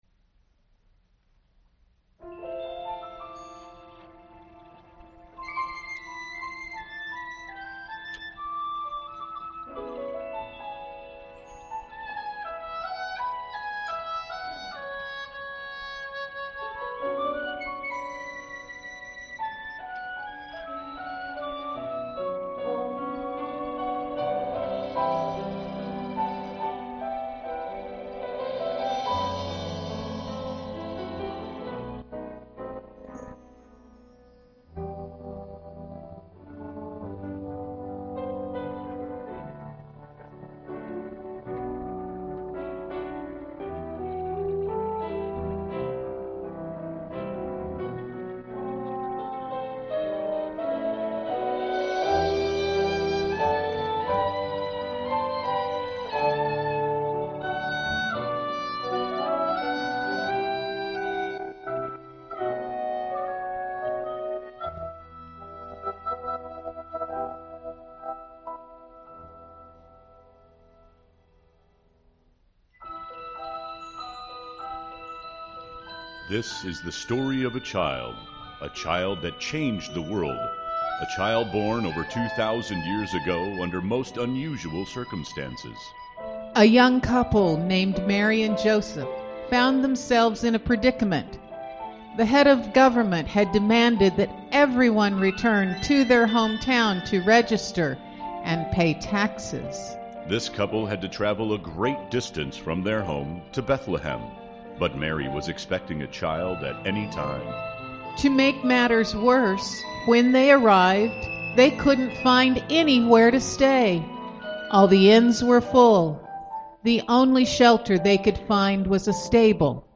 Cantata 12.20.2015